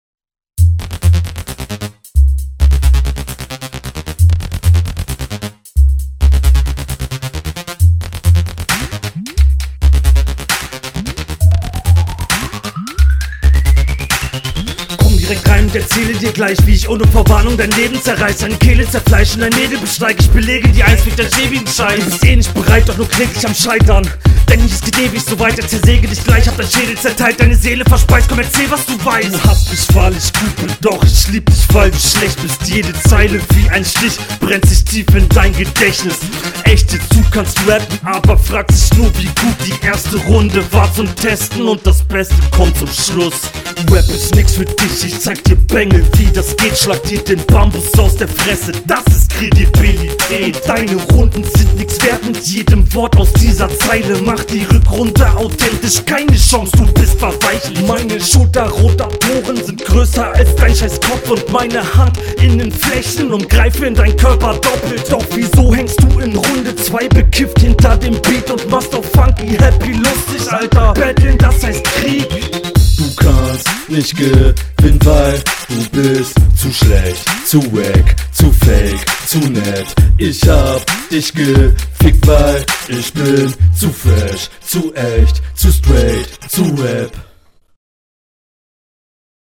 stimme bisi dumpf. wieder der gepresste stimmeinsatz. klingt nicht so souverän wie du das machst.